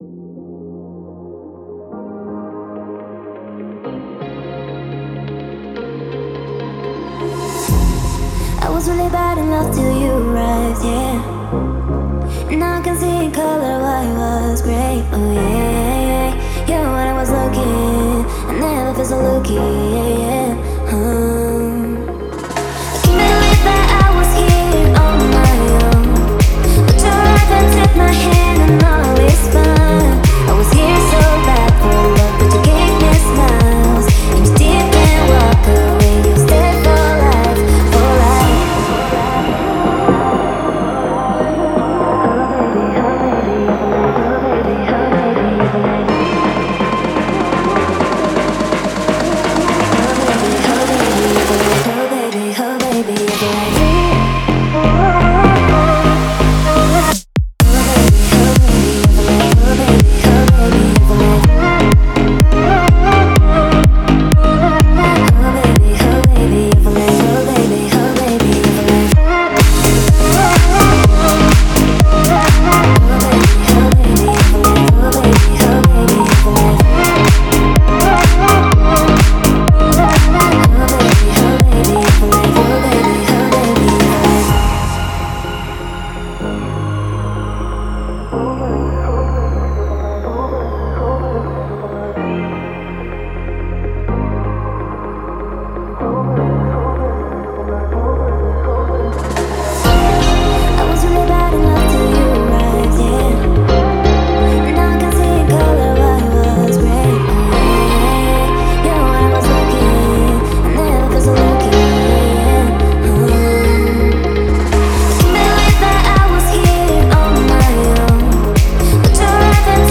это энергичная танцевальная композиция в жанре EDM